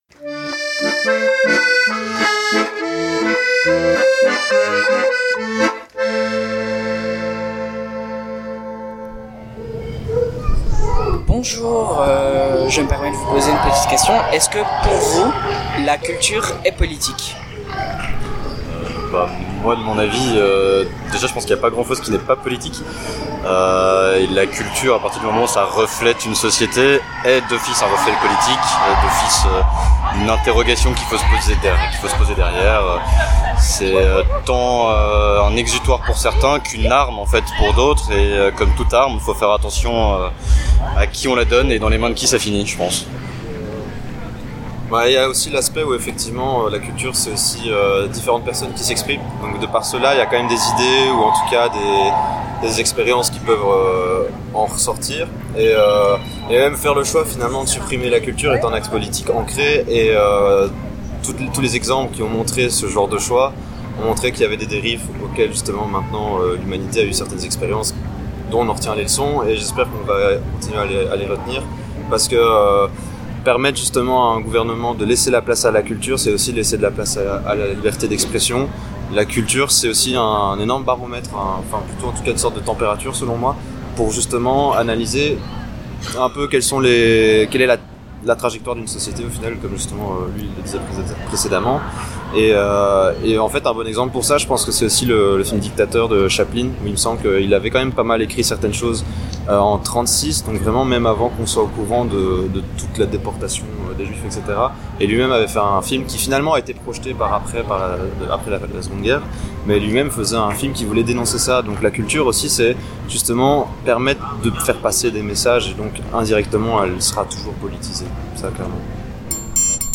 Interview des festivalier.e.s